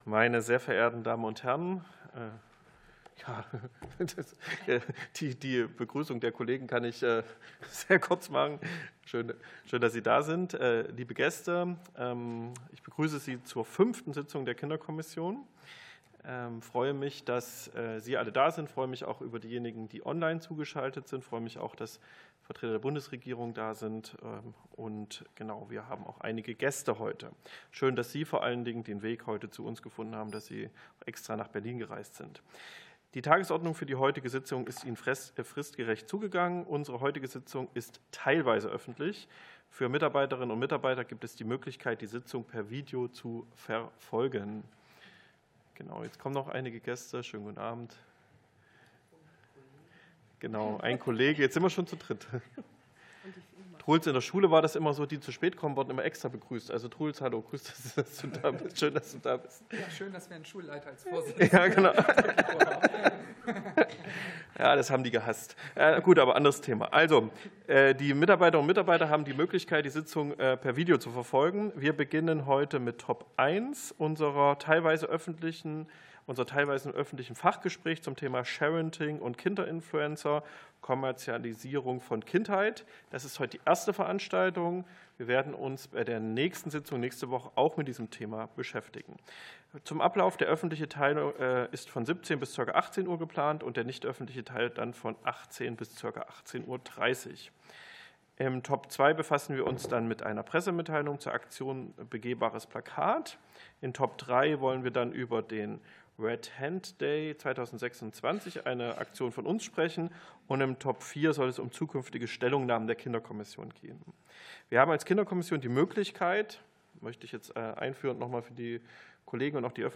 Fachgespräch der Kinderkommission (1. Teil)